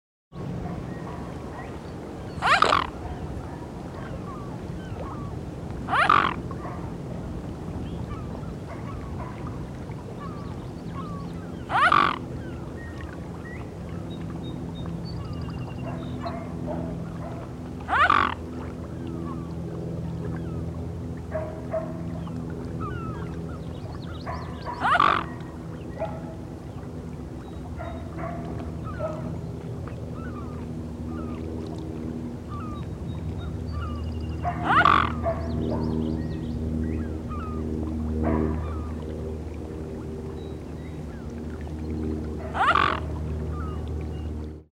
Geneva: Mute Swan (Cygnus olor)